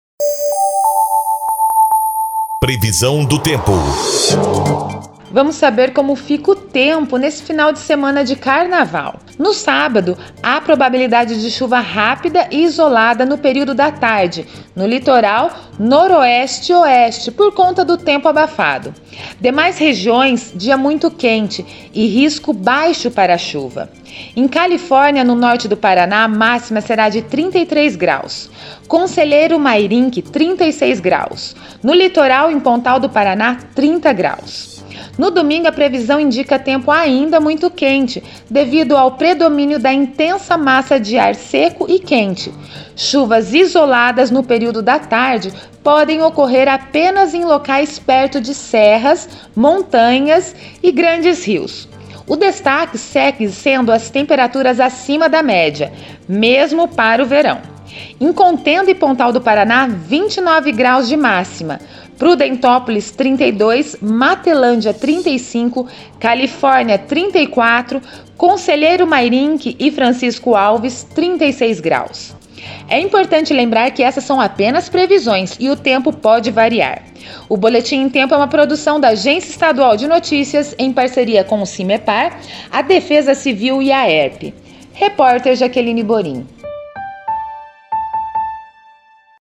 Previsão do Tempo Fim de Semana (10 e 11/02)